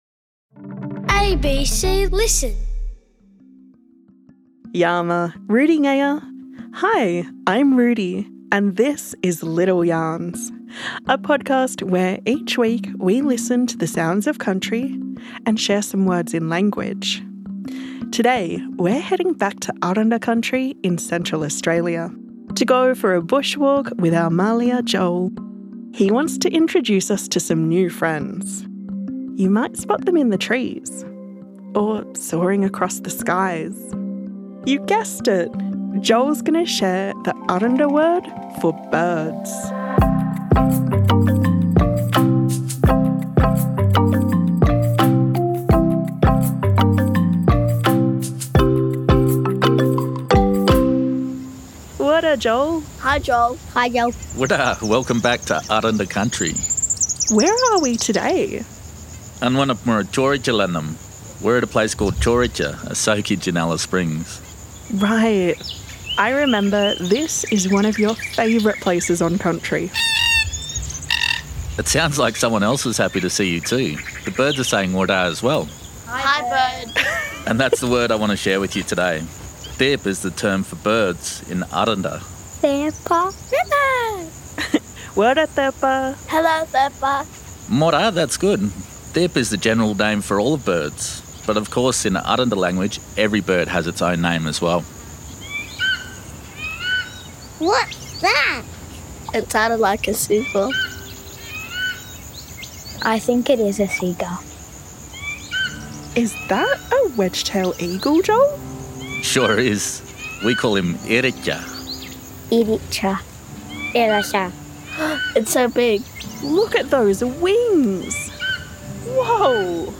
We'll meet some special feathered friends, listen to their calls and learn how to help look after Country.